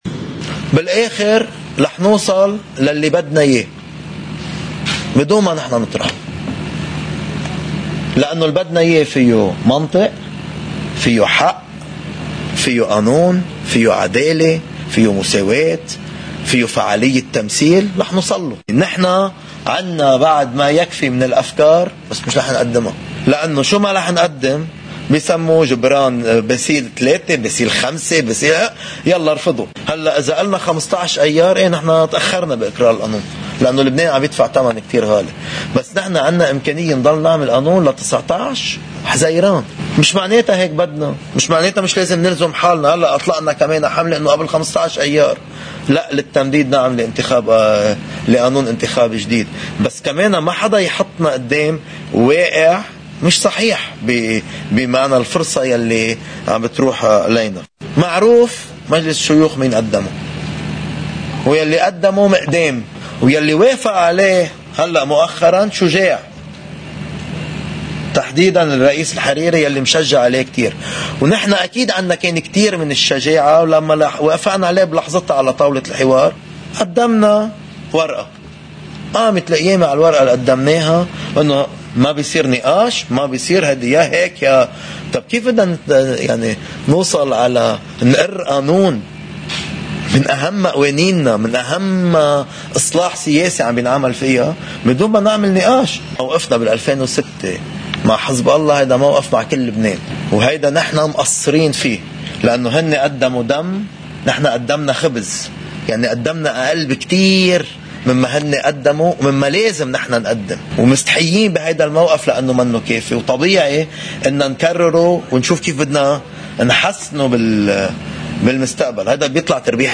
مقتطف من حديث رئيس حزب التيار الوطني الحرّ جبران باسيل بعد اجتماع تكتّل التغيير والإصلاح: